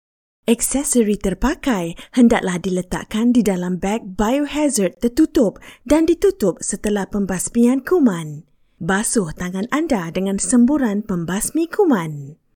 配音风格： 年轻 大气 稳重 知性 清新 时尚 活力 自然 可爱 可爱